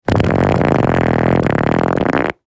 Harley Davidson Fart Ab Bouton sonore